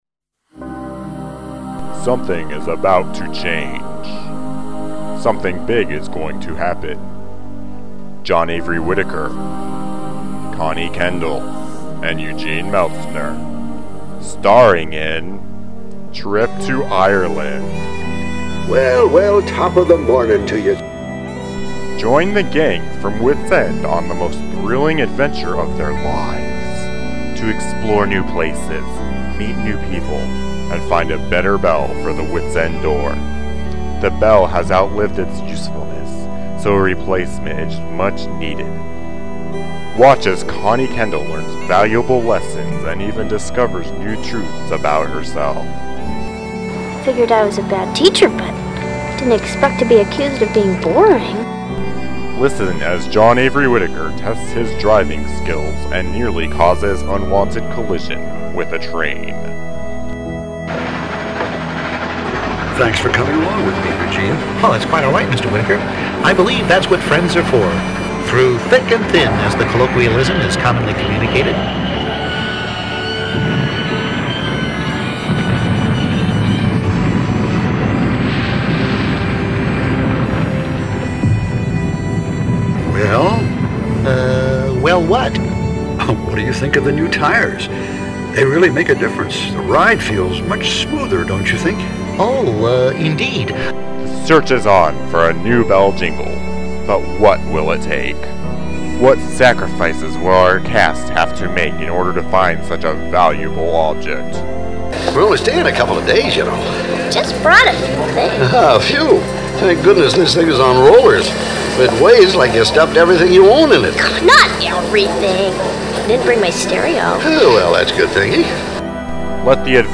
The low, sinister voice heard in this production is not a computer-generated voice.
During production, collection of numerous audio bytes of characters from the show was tedious and overwhelming.